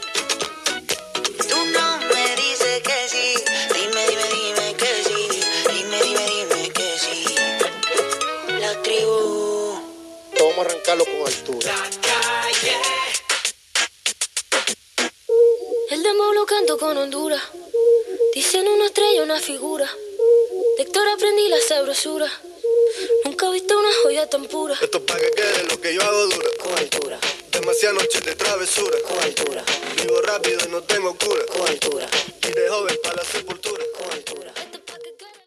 Tema musical, indicatiu de l'emissora i tema musical